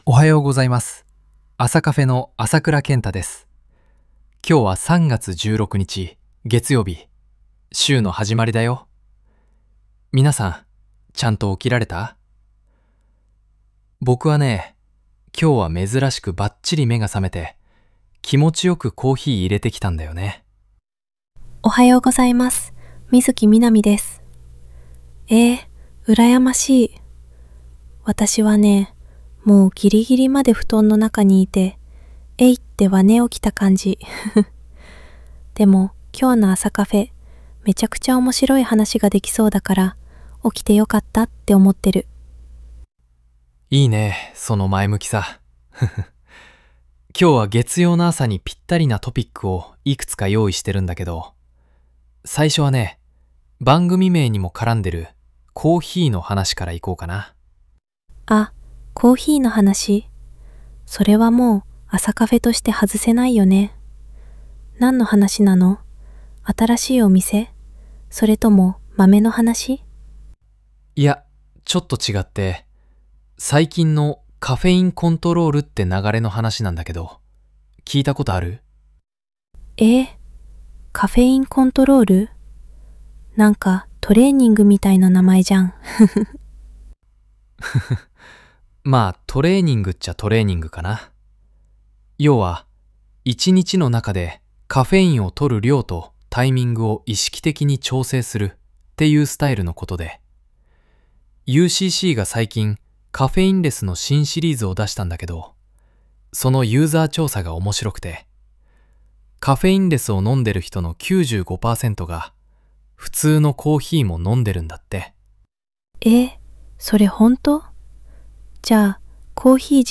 月曜の朝にぴったりなトピックをお届け。コーヒーのカフェインコントロール新常識、 進化しすぎた冷凍食品、国立公園指定記念日の雑学、渋沢栄一の意外なエピソードなど、 ゆるく語り合います。